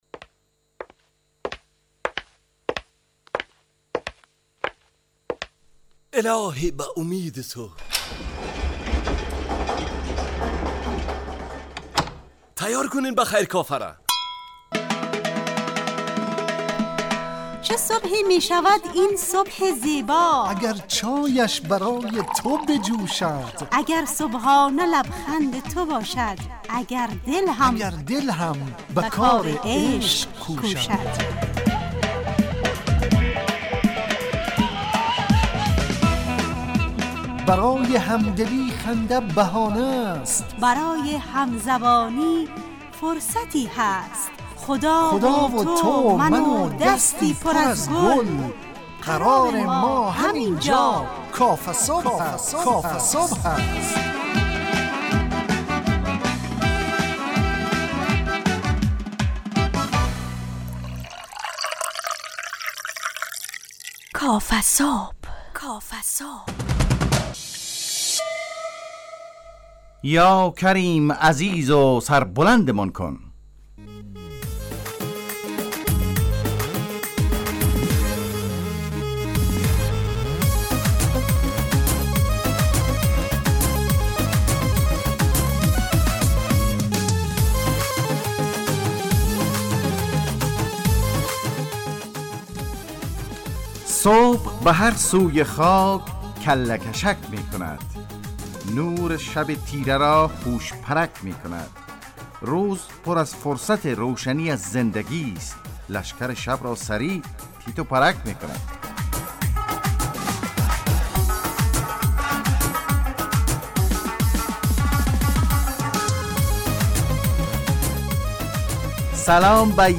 کافه‌صبح – مجله‌ی صبحگاهی رادیو دری با هدف ایجاد فضای شاد و پرنشاط صبحگاهی همراه با طرح موضوعات اجتماعی، فرهنگی و اقتصادی جامعه افغانستان با بخش‌های کارشناسی، نگاهی به سایت‌ها، گزارش، هواشناسی٬ صبح جامعه، گپ صبح و صداها و پیام‌ها شنونده‌های عزیز